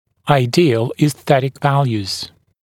[aɪ’dɪəl iːs’θetɪk ‘væljuːz][ай’диэл и:с’сэтик ‘вэлйу:з]идеальные эстетические параметры